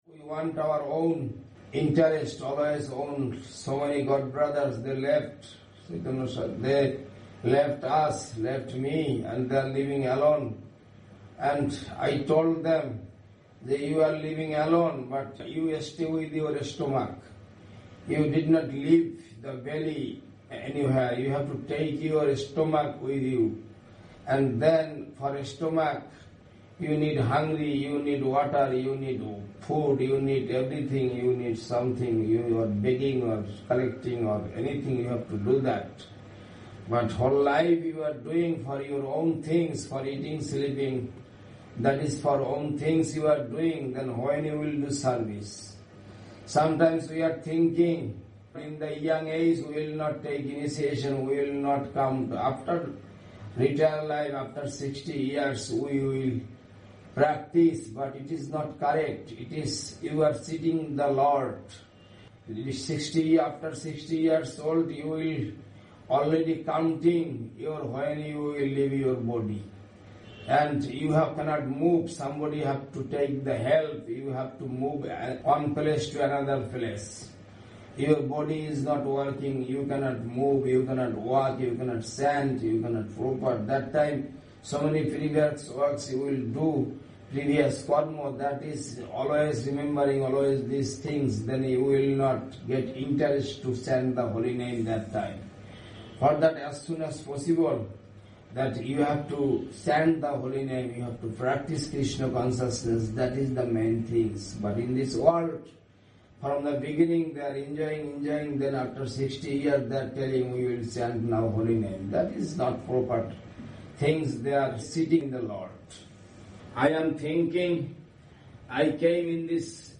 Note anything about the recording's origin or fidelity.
Speaking online to the devotees in Quezon City, Philippines